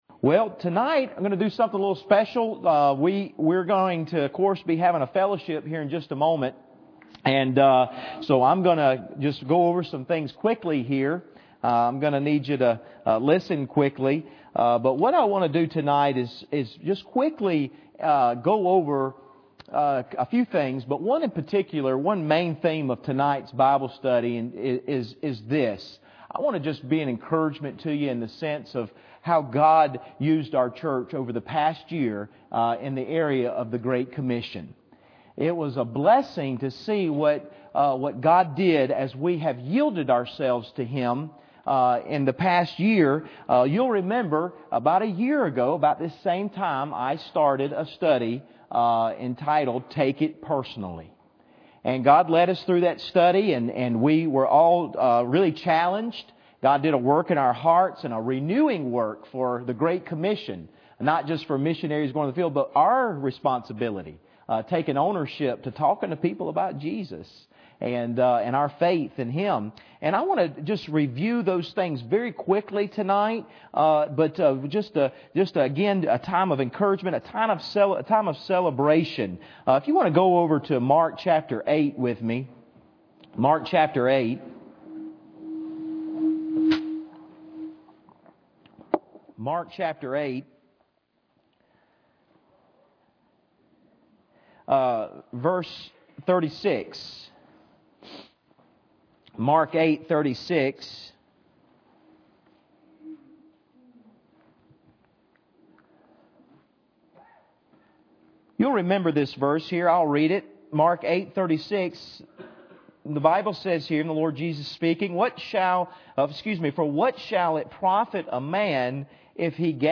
Mark 8:36 Service Type: Wednesday Evening Bible Text